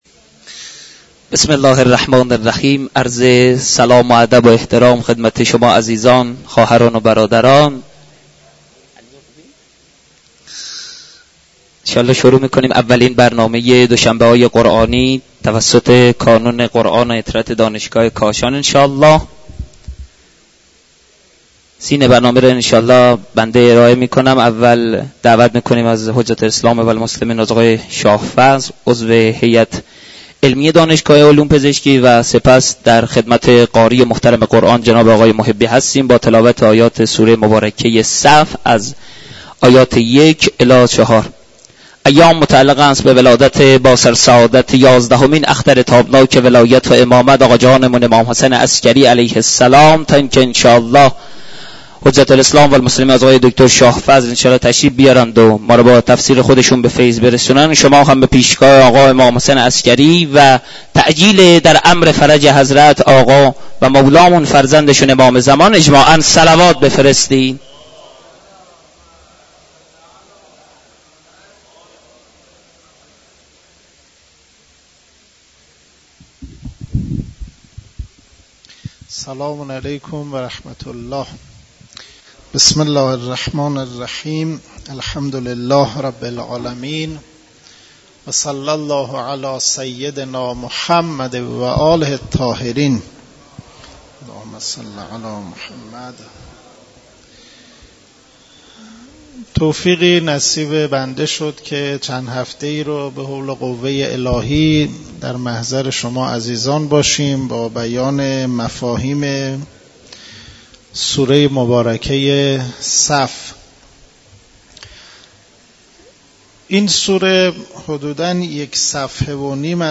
مراسم معنوی دوشنبه های قرآنی در مسجد دانشگاه کاشان برگزار شد.